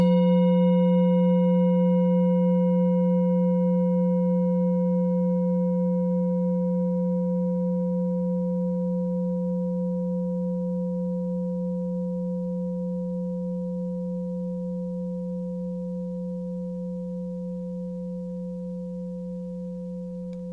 Klangschale Orissa Nr.9
Klangschale-Gewicht: 990g
Klangschale-Durchmesser: 18,9cm
Sie ist neu und wurde gezielt nach altem 7-Metalle-Rezept in Handarbeit gezogen und gehämmert.
(Ermittelt mit dem Filzklöppel)
klangschale-orissa-9.wav